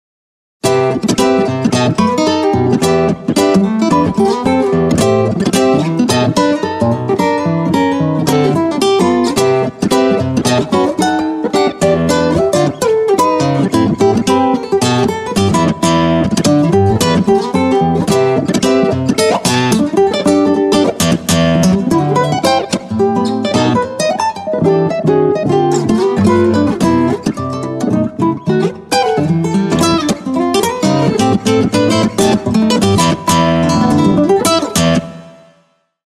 Virtual nylon Guitar